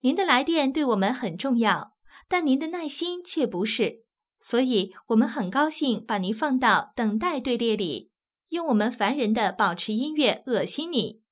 ivr-on_hold_indefinitely.wav